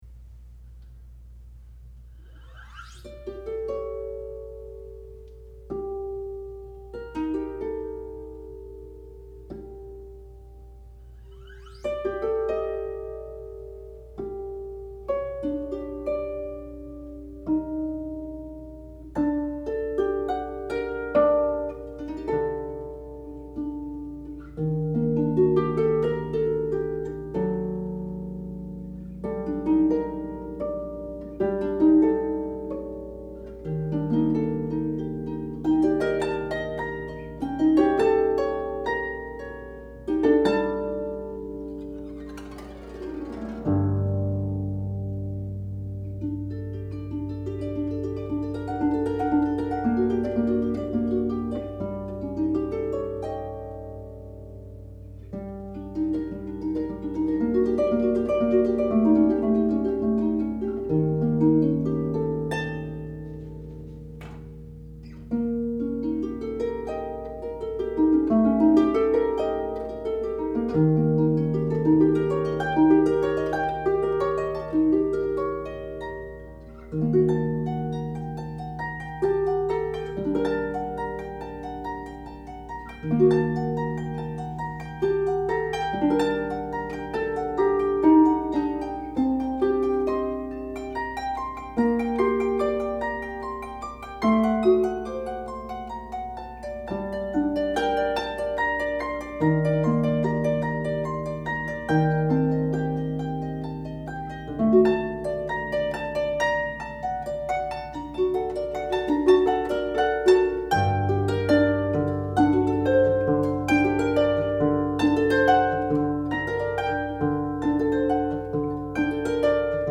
Hauntingly beautiful.